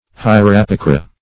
Search Result for " hierapicra" : The Collaborative International Dictionary of English v.0.48: Hierapicra \Hi"e*ra*pi"cra\, n. [NL., fr. Gr.
hierapicra.mp3